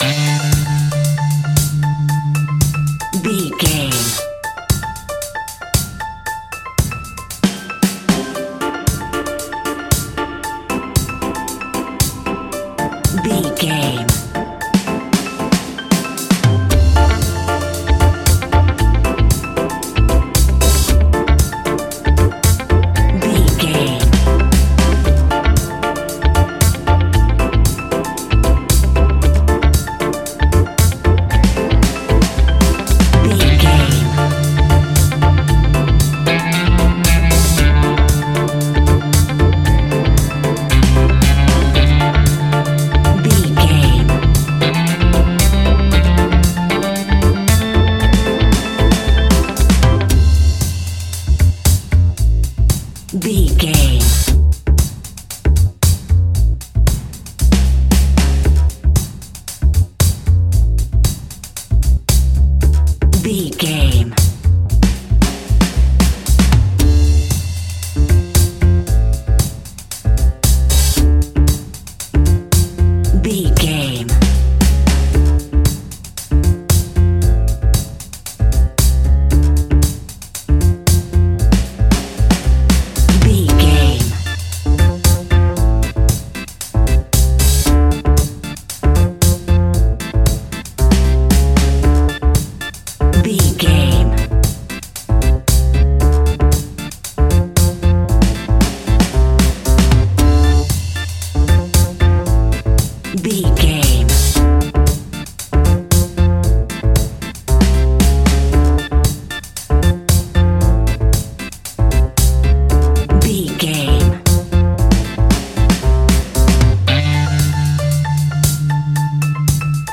Aeolian/Minor
D
laid back
chilled
skank guitar
hammond organ
percussion
horns